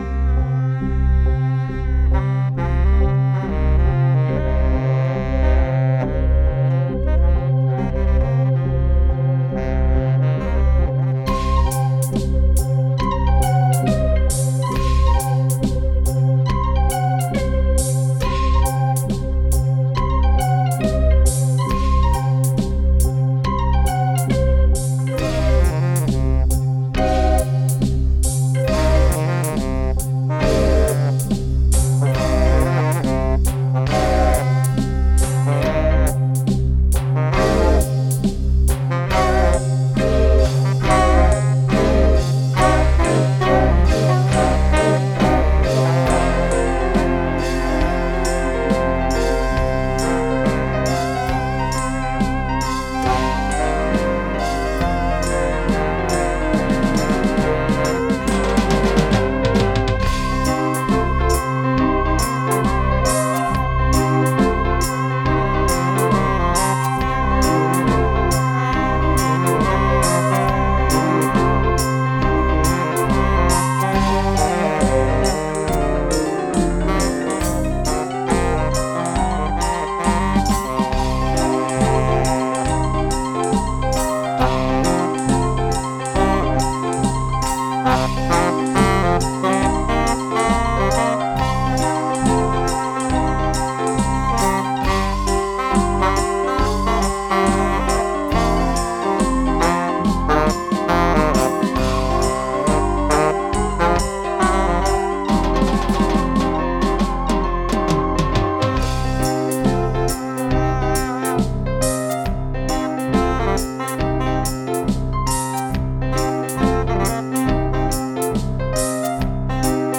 Genre: Freie Musik - Blues